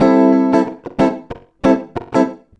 92FUNKY  6.wav